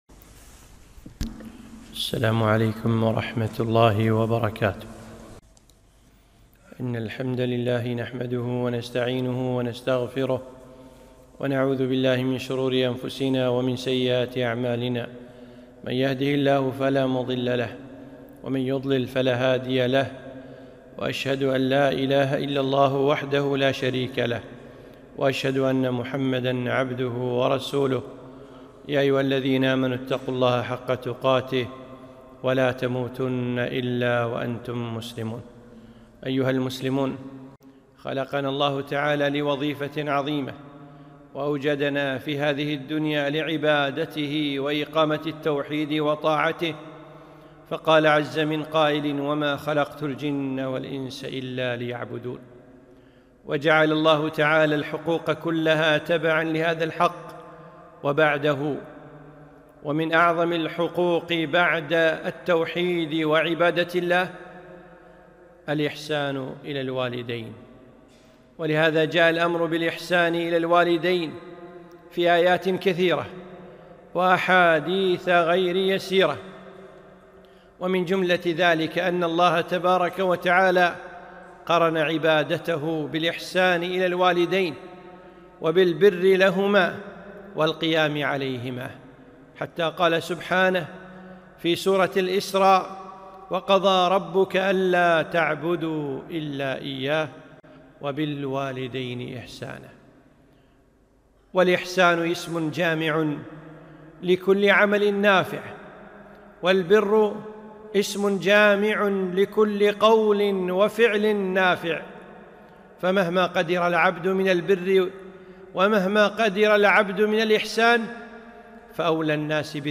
خطبة - وبالوالدين إحسانا